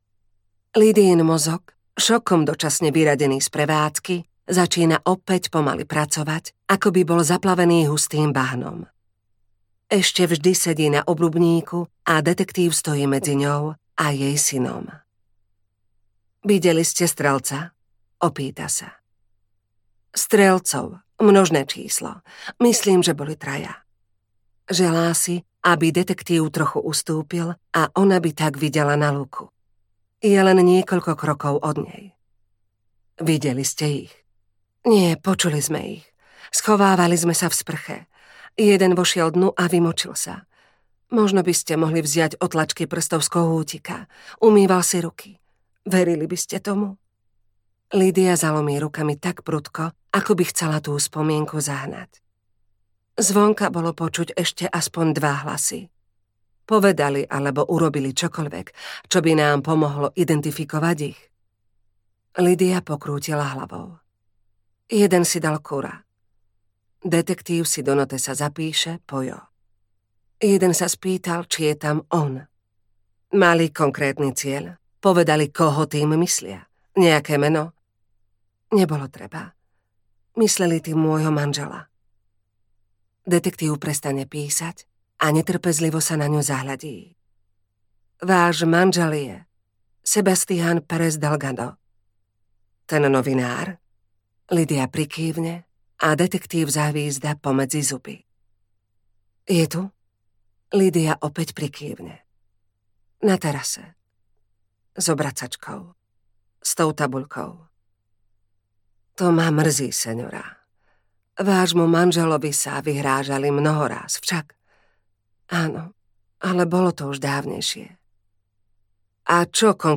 Americká zem audiokniha
Ukázka z knihy